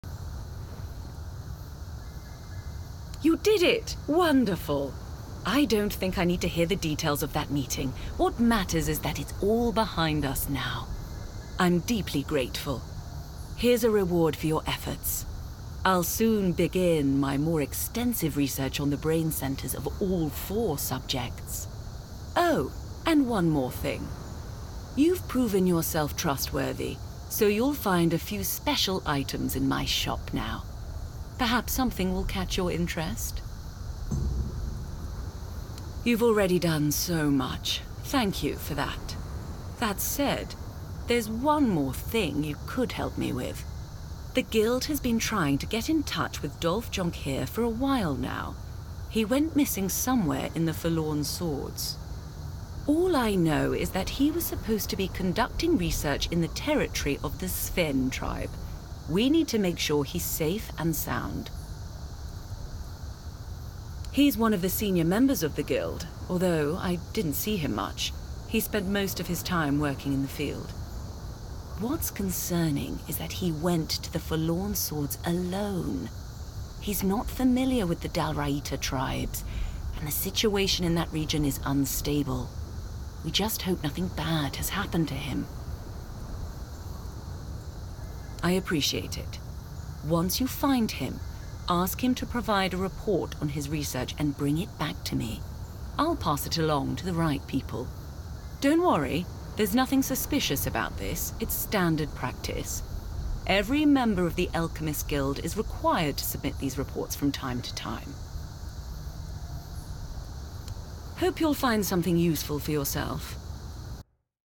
Cold, commanding character voice - Tainted Grail video game